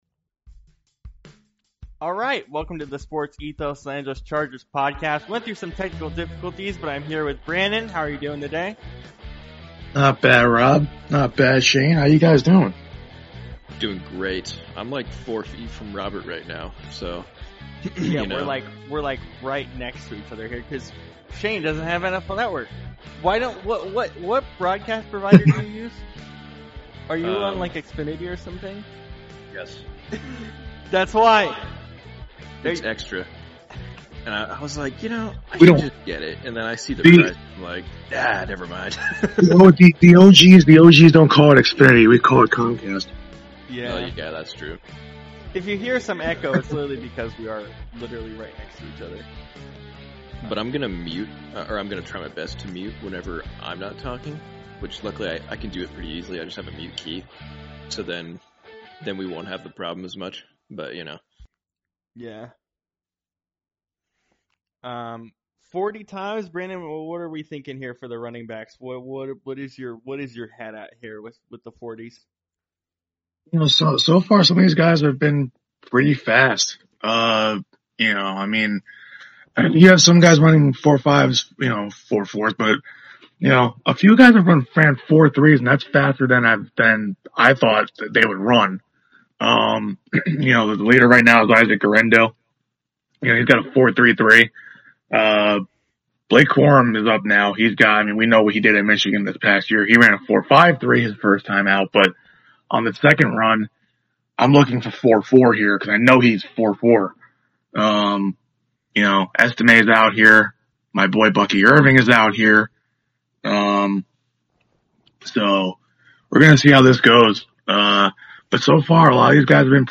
NFL Combine First 4 hours of Day 3 Live